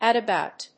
アクセントat abòut[W16-A34D]